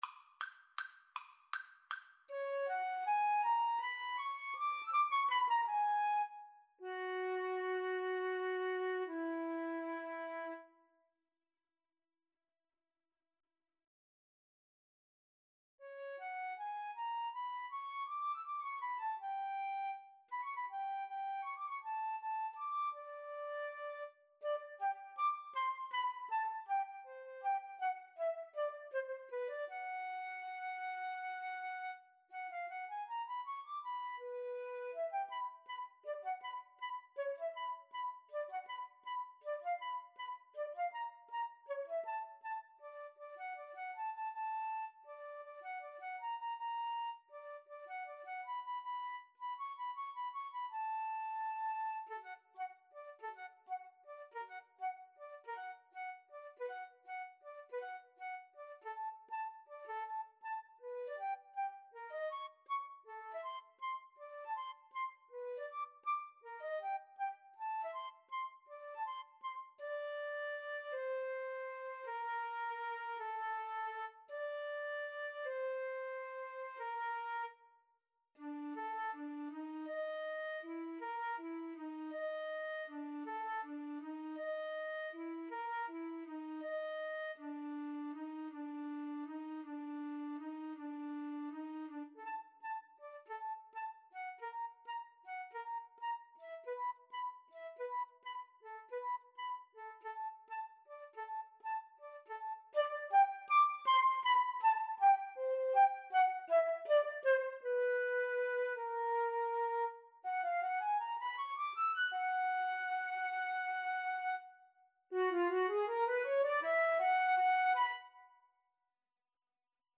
Play (or use space bar on your keyboard) Pause Music Playalong - Player 1 Accompaniment reset tempo print settings full screen
D major (Sounding Pitch) (View more D major Music for Flute-Cello Duet )
Allegretto = 160
Classical (View more Classical Flute-Cello Duet Music)